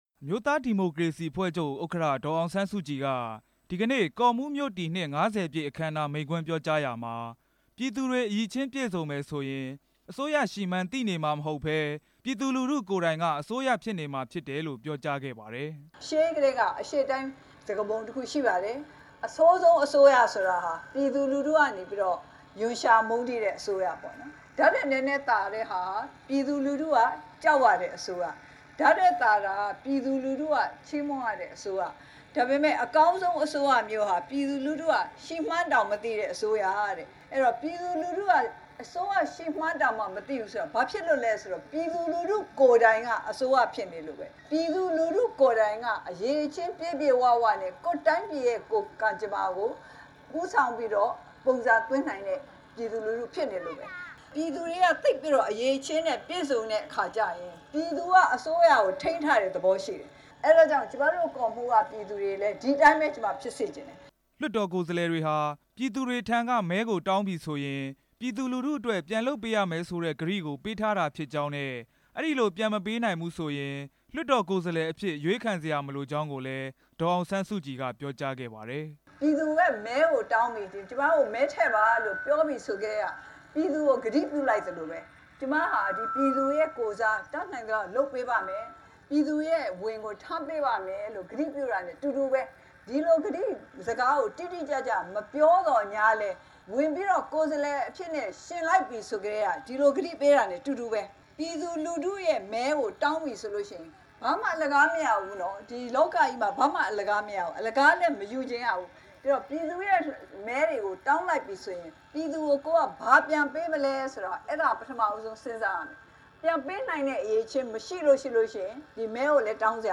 ဒေါ်အောင်ဆန်းစုကြည်ရဲ့ ပြောကြားချက်များ
ရန်ကုန်တိုင်း ကော့မှူးမြို့ အောင်ဇဗ္ဗူအားကစားကွင်းမှာ ကျင်းပတဲ့ ကော့မှူးမြို့တည်နှစ် ၅၀ ပြည့် အခမ်အနား မိန့်ခွန်းပြောကြားရာမှာ ဒေါ်အောင်ဆန်းစုကြည်က အဲဒီလို ပြောခဲ့တာပါ။